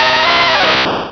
Cri de Racaillou dans Pokémon Rubis et Saphir.